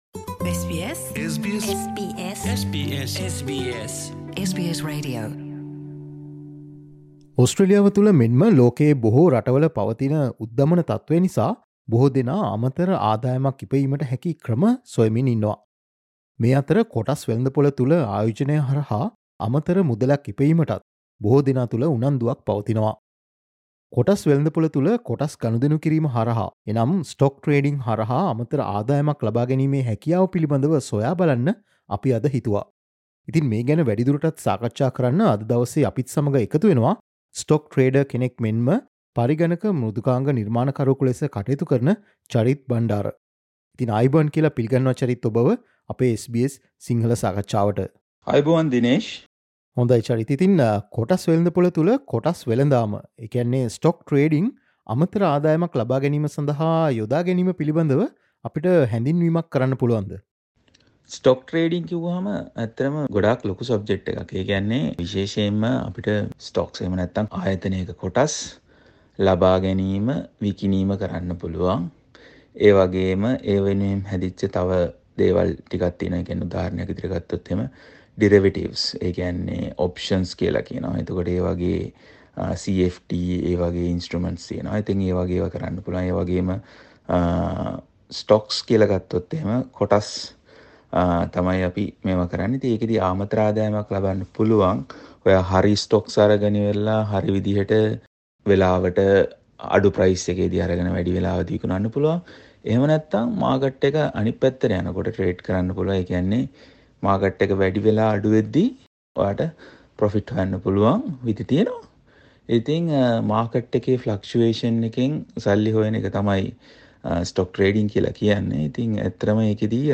There is also the possibility of getting additional income through trading in the stock market. Listen to SBS Sinhala interview for more information.